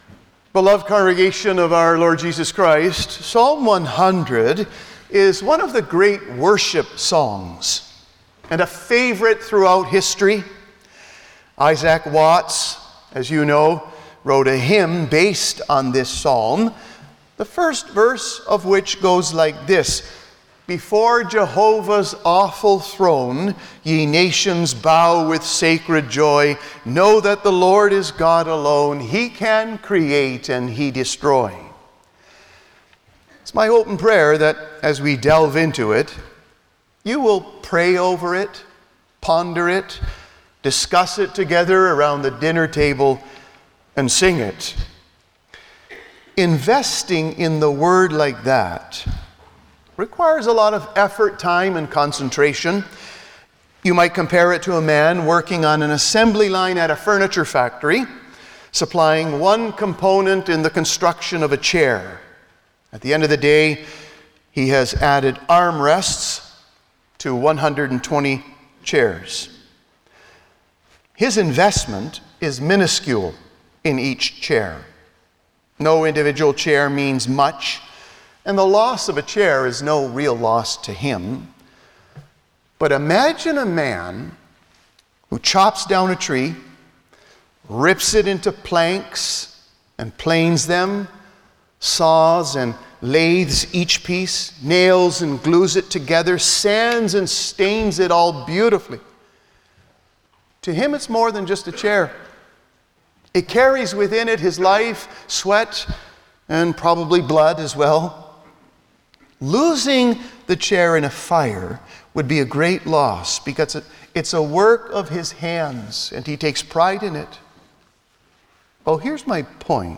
Service Type: Sunday morning
09-Sermon.mp3